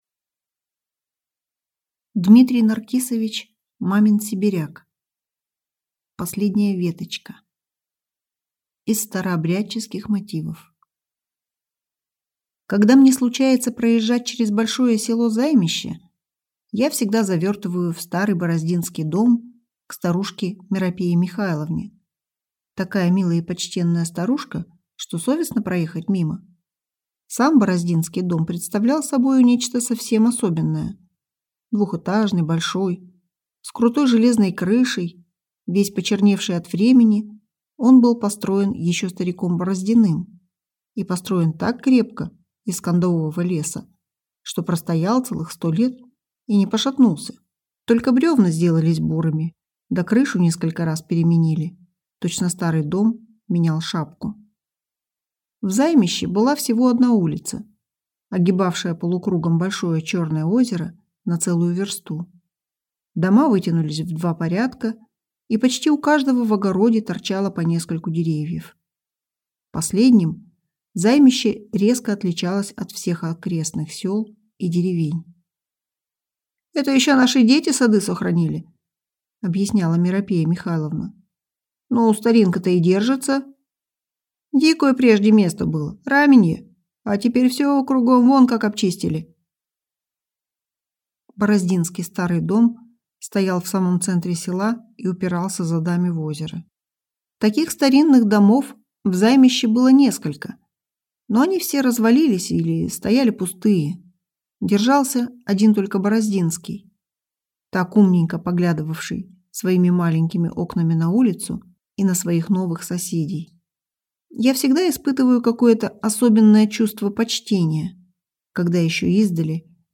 Аудиокнига Последняя веточка | Библиотека аудиокниг